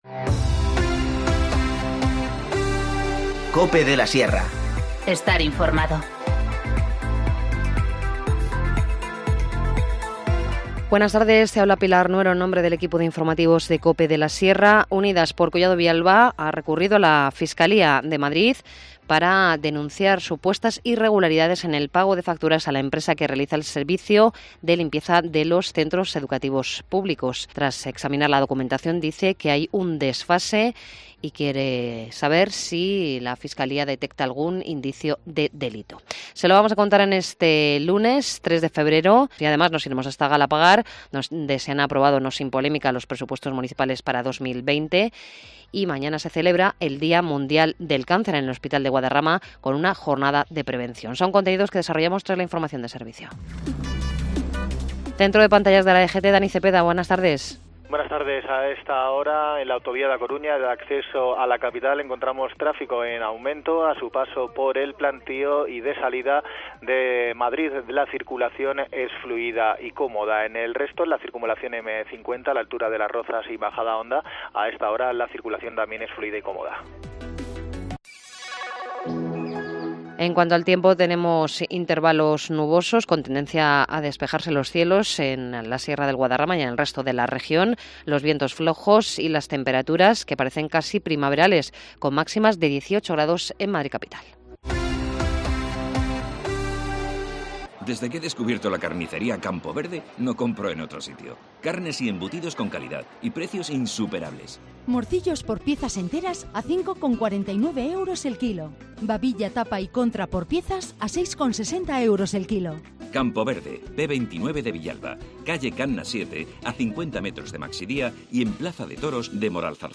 Informativo Mediodía 3 febrero 14:20h